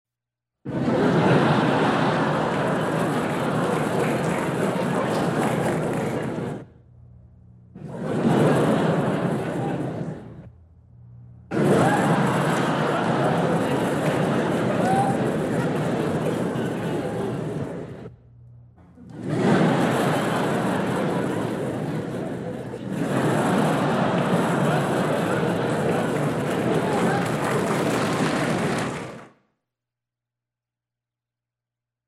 100 Sound Effects Vol. 2 - 40 - Laughing Audience - Madacy Music Group Inc.